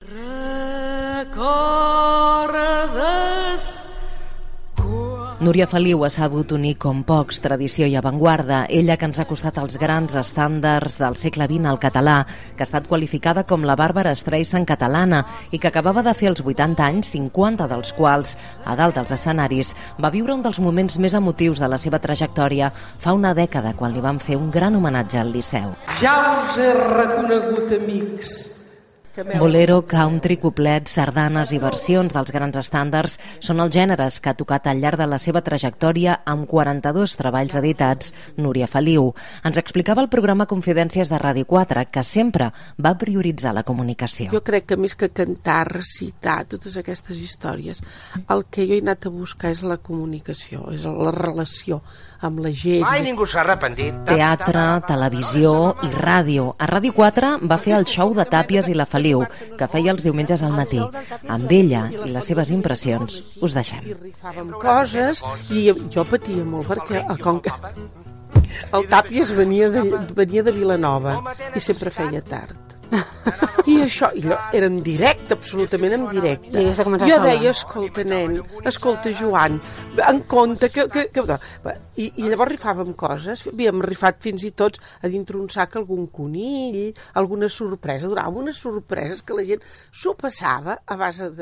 Informació de la mort de la cantant Núria Feliu Gènere radiofònic Informatiu